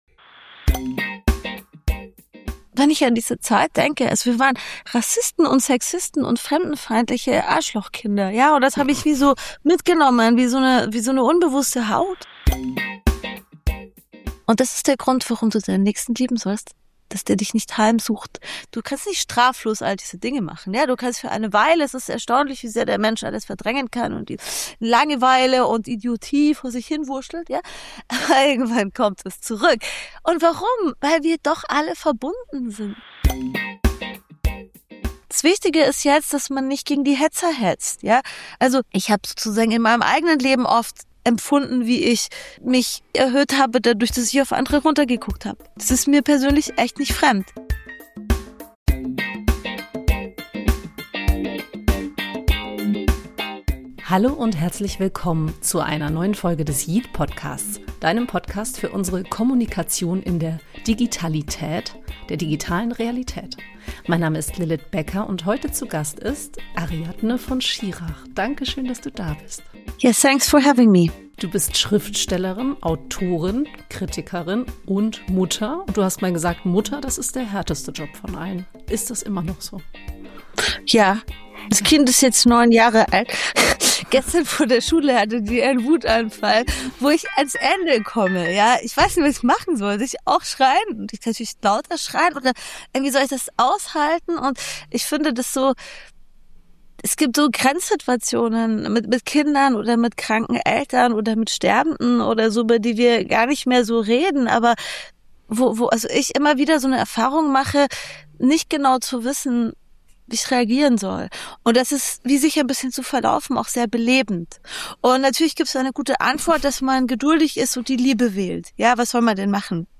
Ein Gespräch über Lebenskunst, digitale Identität und christliche Werte, das Mut macht, die eigene innere Arena ernst zu nehmen – und die Welt ein bisschen schöner zu hinterlassen.